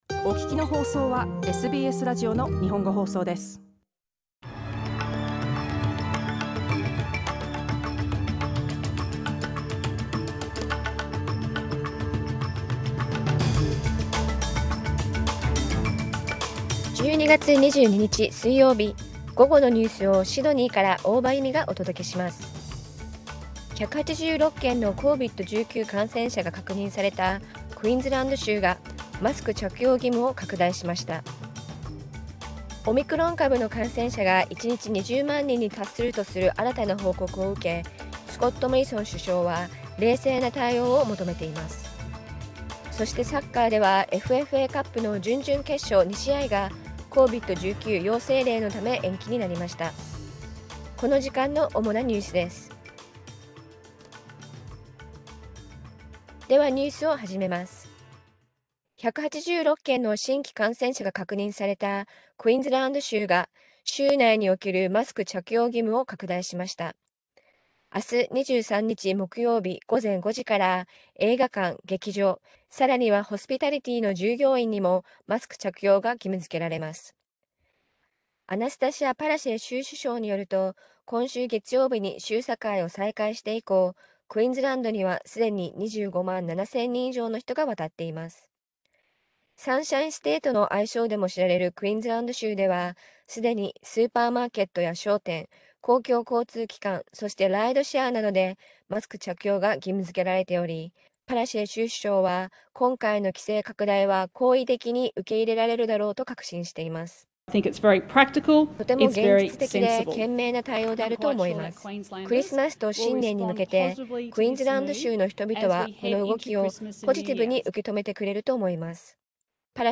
12月22日午後のニュース
Afternoon news in Japanese, 22 December 2021